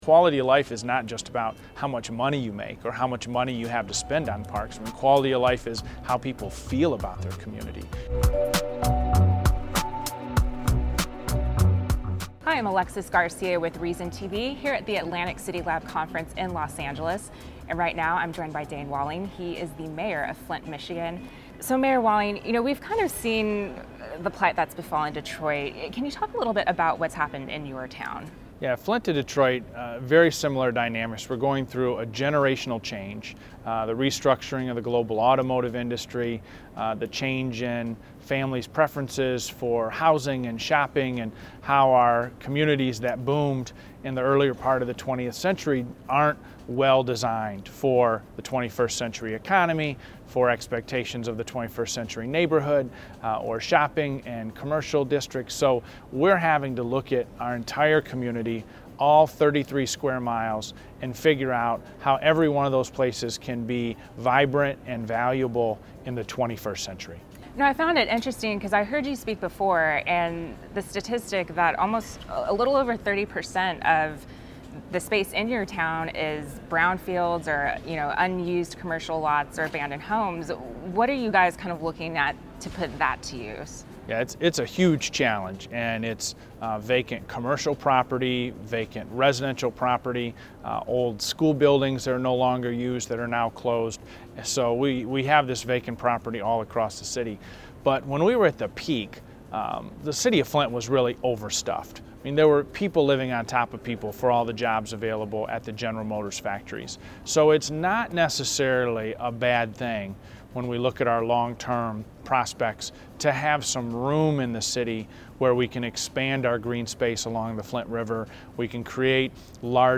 To reverse the pattern of urban flight and decay, Flint mayor Dayne Walling has made it his priority to bring revive the manufacturing town by reforming outdated zoning codes and engaging citizen groups to take part in city planning. He talked about these efforts with Reason TV at the recent Atlantic CityLab conference in Los Angeles, California.